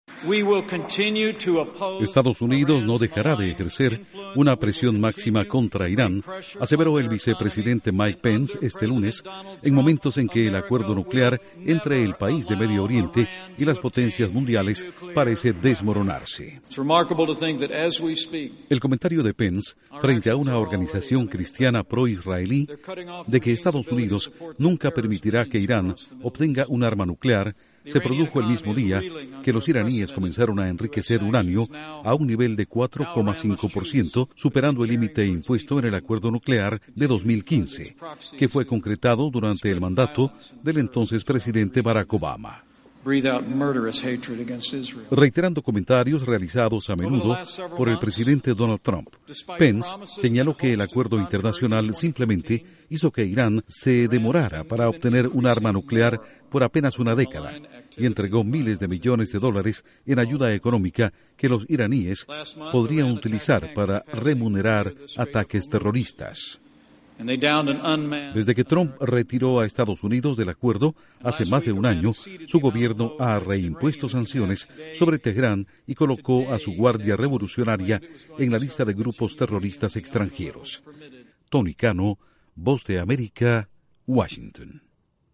Vicepresidente Pence dice que Estados Unidos no dejará de presionar a Irán. Informa desde la Voz de América en Washington